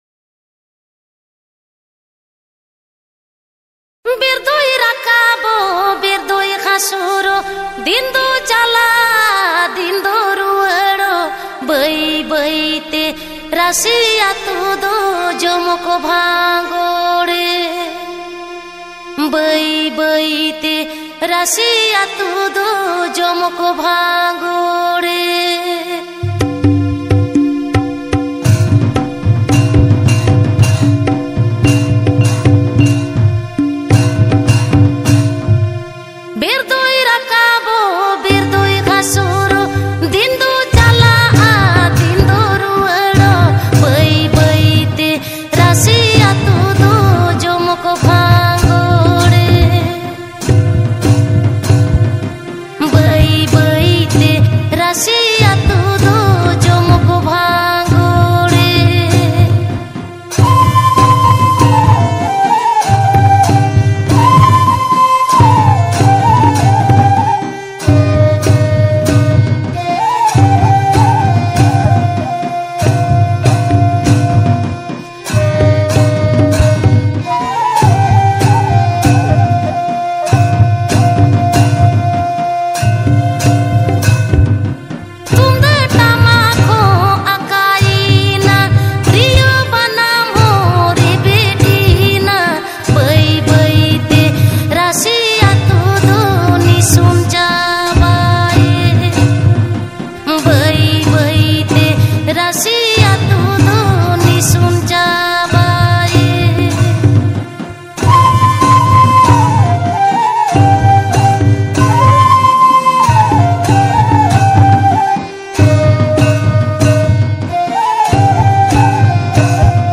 Santali song